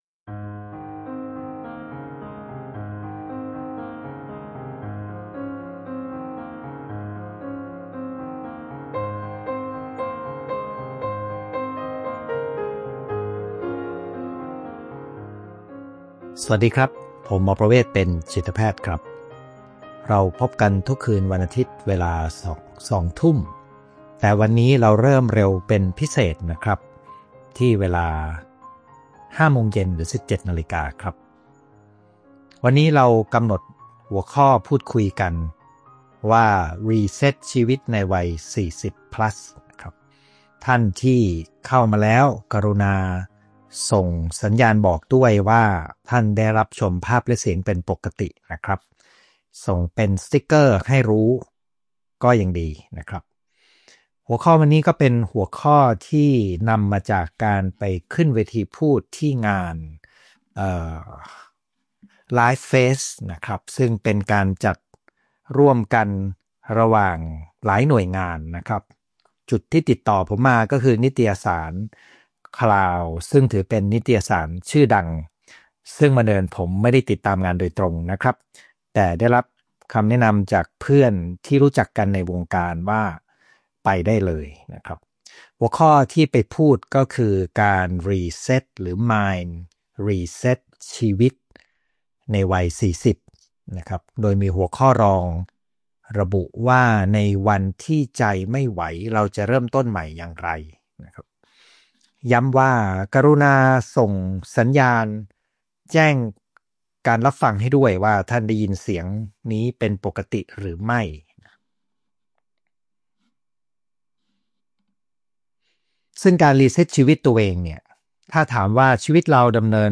ไลฟ์ประจำวันอาทิตย์ที่ 19 ตุลาคม 2568 เวลาสองทุ่ม